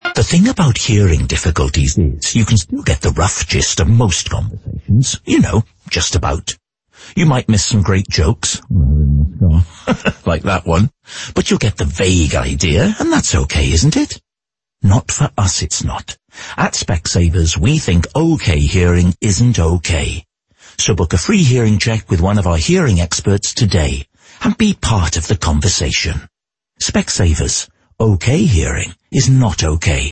Our ad of the month is Hearing Test, which uses the consistent brand voice of Rob Brydon. The execution blots out some key words grabbing the listener’s attention, and it uses a tone that is both informative and empathetic.